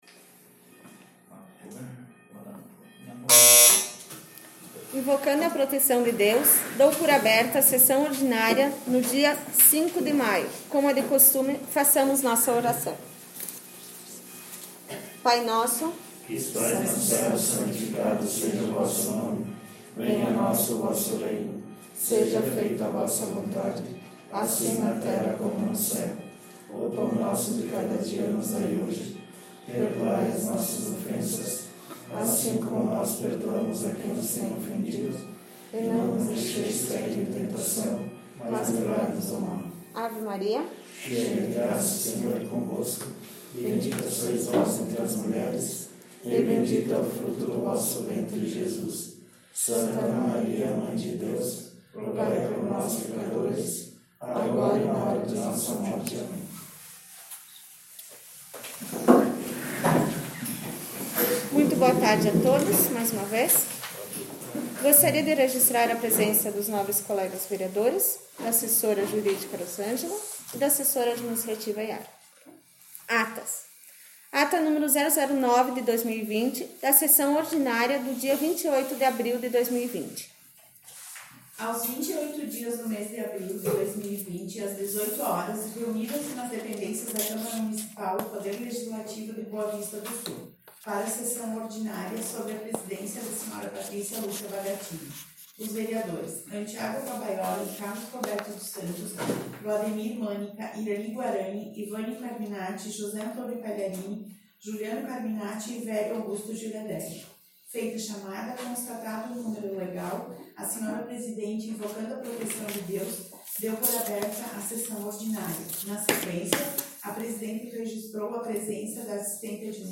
Sessão Ordinária 05/05/2020 — Câmara Municipal de Boa Vista do Sul